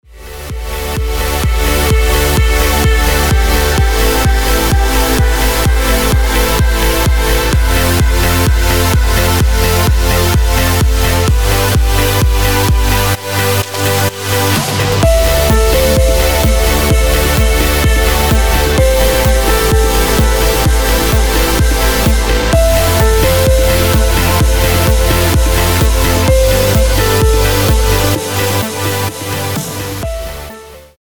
громкие
Electronic
EDM
без слов
Стиль: progressive trance